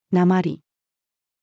鉛-female.mp3